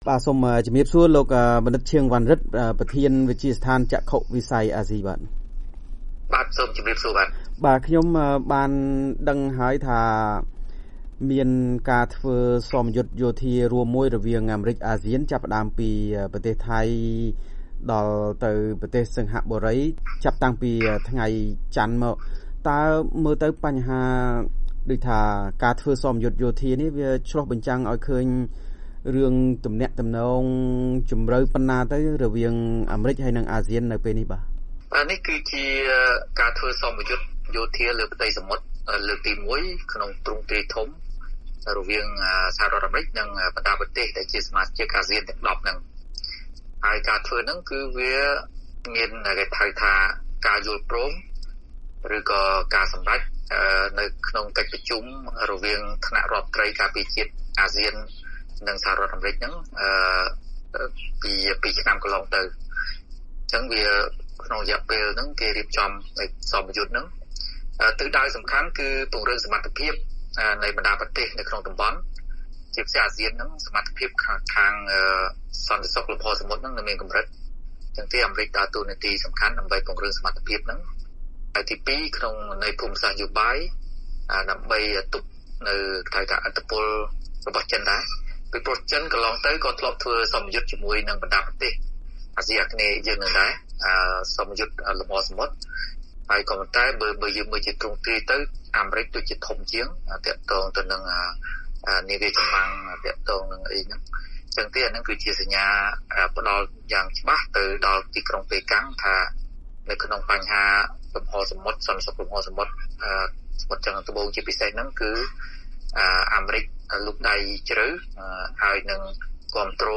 បទសម្ភាសន៍ VOA៖ សមយុទ្ធយោធាអាមេរិក-អាស៊ាន បង្ហាញពីឧត្តមភាពយោធាអាមេរិកក្នុងតំបន់ដែលចិនច្បាមឥទ្ធិពល